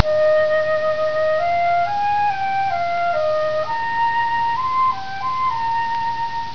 Solution:  You make the files by dividing and then multiplying by 2 (7 bits), dividing and then multiplying by 4 (6 bits), etc., all the way up to dividing and then multiplying by 128, which uses only 1 bit to record each amplitude.
flute6.wav